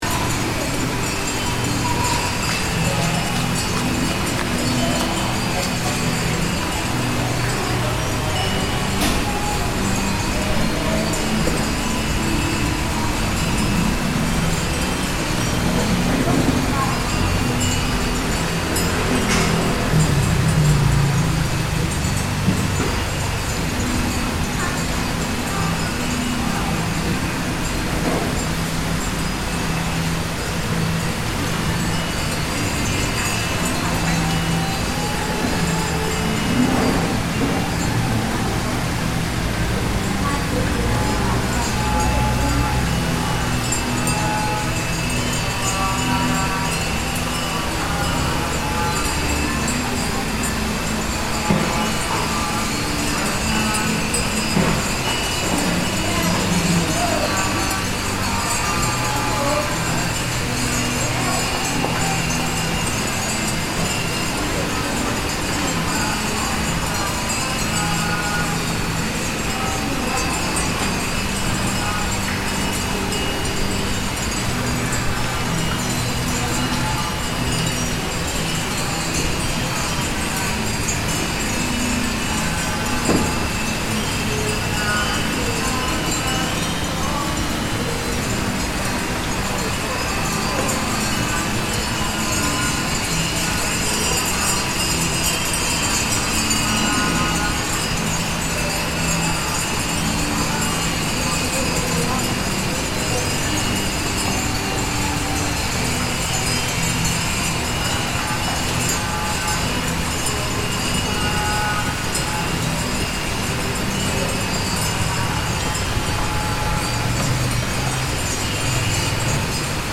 Outside the towering gold Buddha, the sound of soft clinking of wind chimes swaying in the breeze can be heard mingling with the sounds of the city and water features in the background.